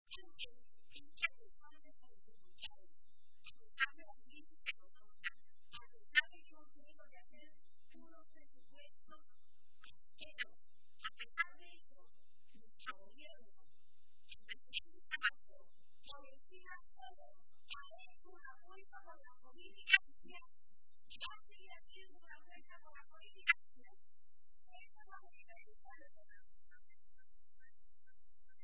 Barreda realizó estas manifestaciones durante la celebración de la tradicional comida navideña del PSOE de Toledo, que ha tenido lugar hoy en la capital regional, y a la que también asistió la ministra de Sanidad y Política Social, Trinidad Jiménez.